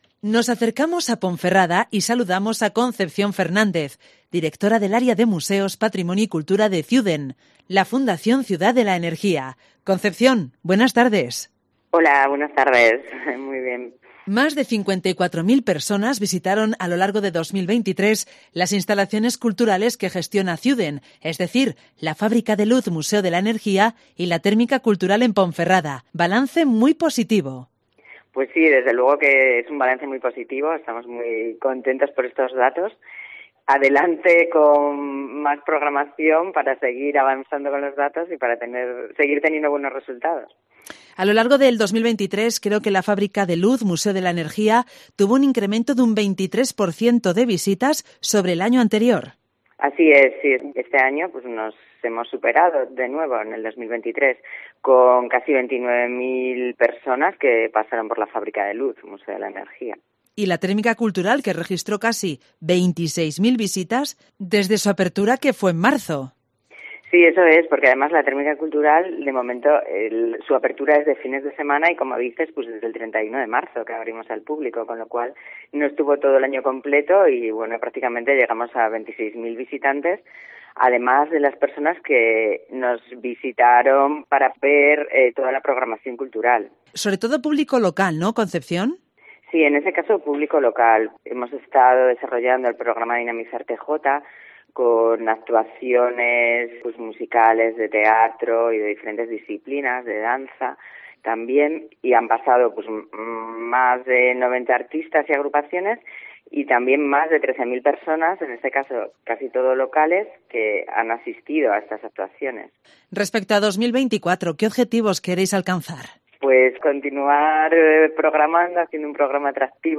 León